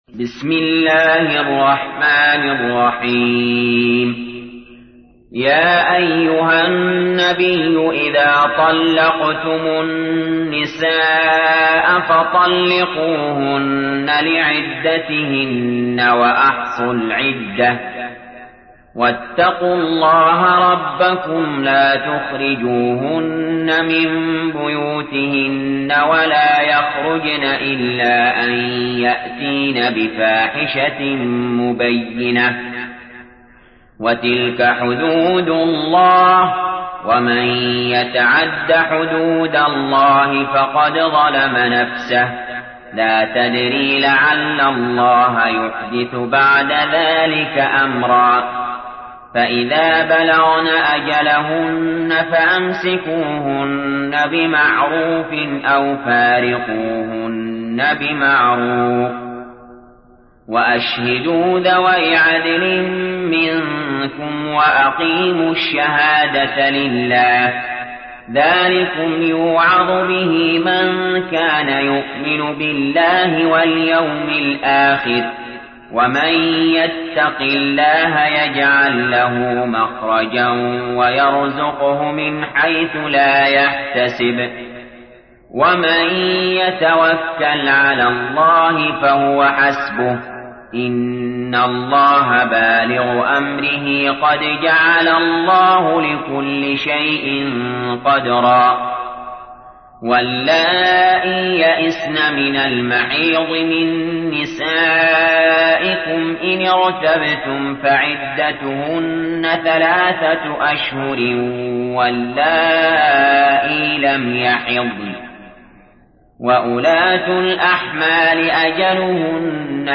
تحميل : 65. سورة الطلاق / القارئ علي جابر / القرآن الكريم / موقع يا حسين